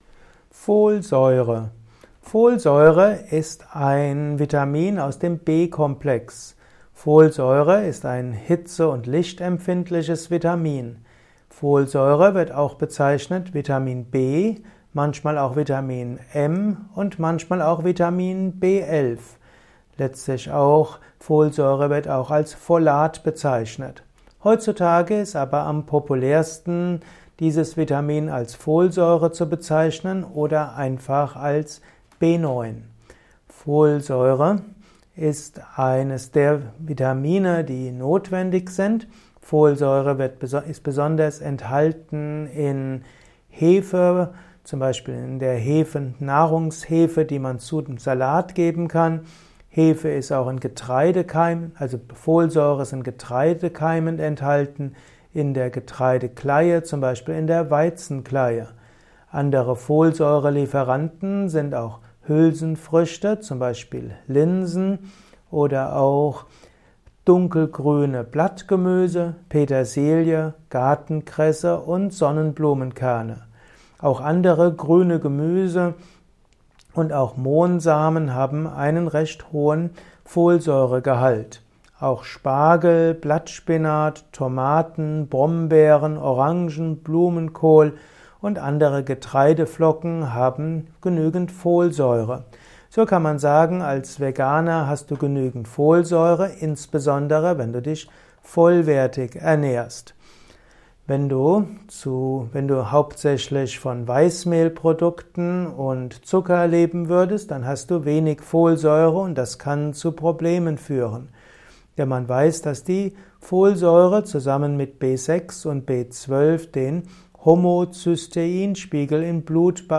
Ein Kurzvortrag über Folsäure